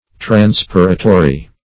Transpiratory \Tran*spir"a*to*ry\, a.